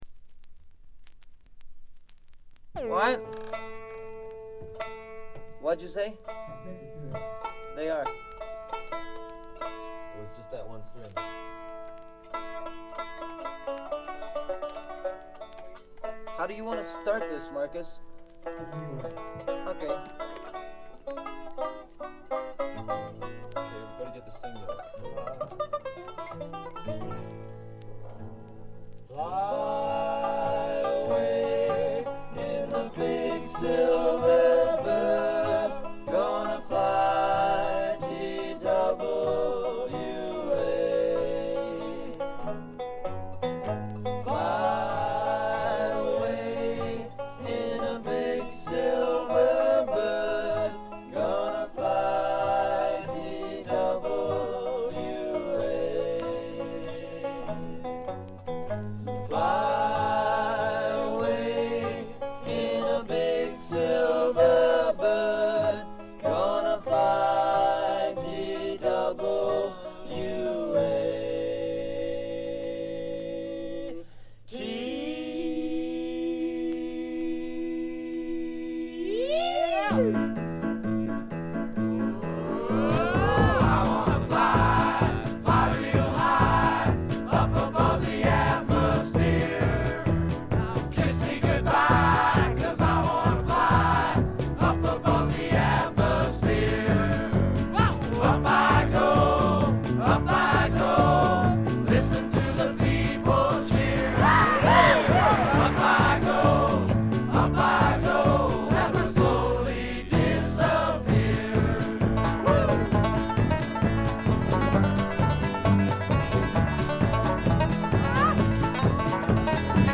部分試聴ですが静音部での軽微なチリプチ程度。
フォーク、ロック、ポップをプログレ感覚でやっつけたというか、フェイクで固めたような感覚とでもいいましょうか。
試聴曲は現品からの取り込み音源です。